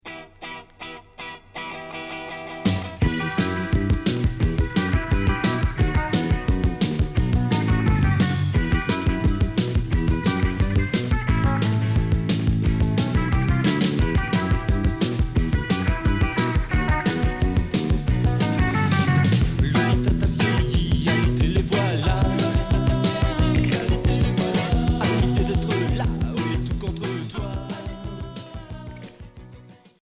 Rock Troisième 45t retour à l'accueil